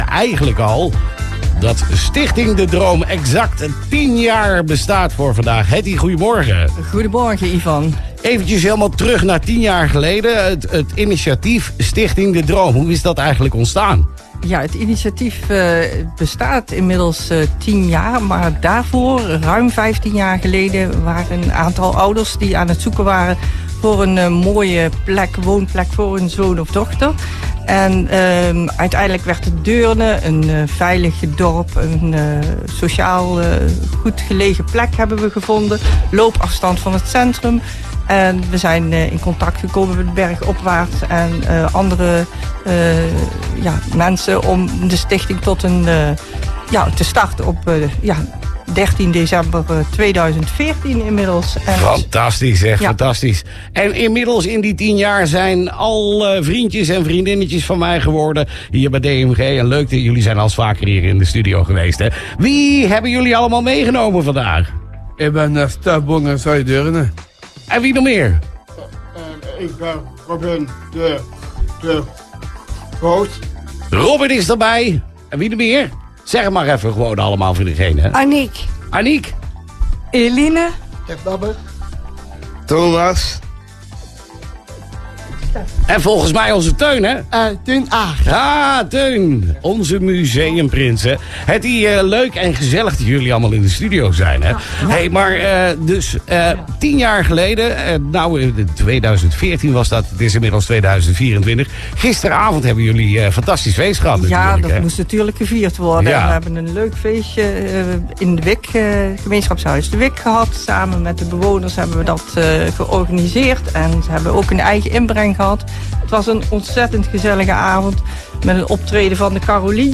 Audio: Interview DMG radio - Stichting de Droom
Eén dag na ons jubileumfeest waren wij te gast bij DMG Radio.